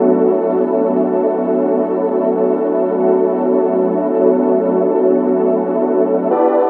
Pad_143_F#.wav